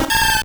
Cri de Macronium dans Pokémon Or et Argent.